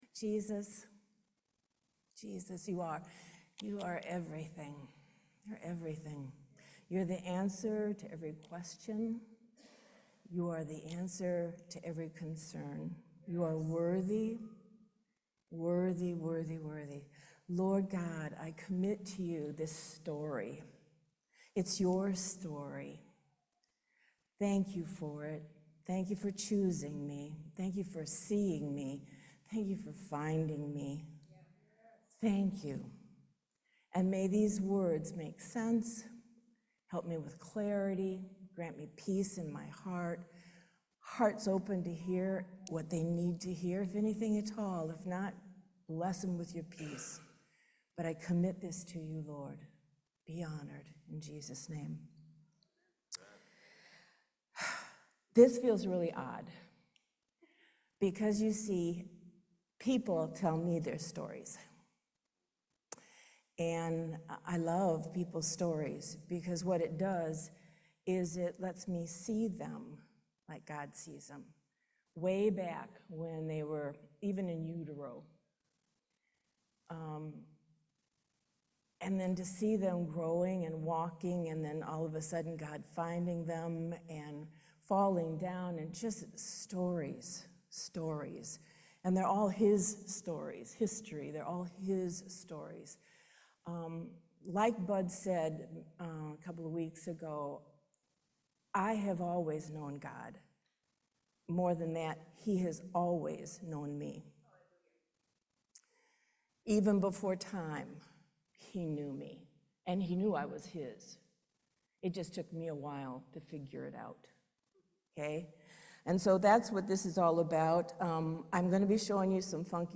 Series: Testimony
Service Type: Main Service